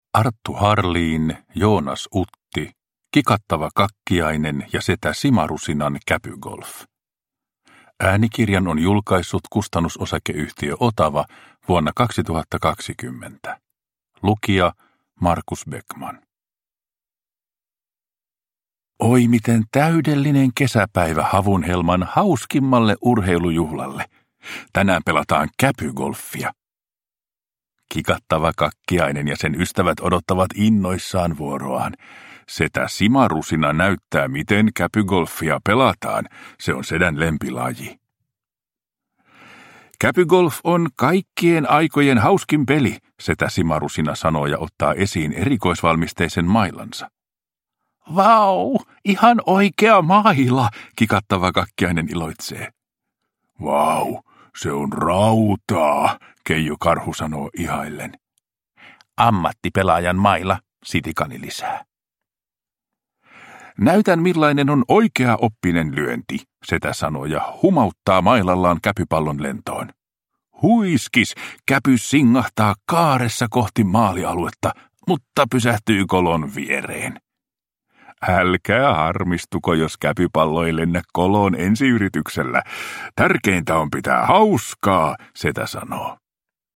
Kikattava Kakkiainen ja Setä Simarusinan käpygolf – Ljudbok – Laddas ner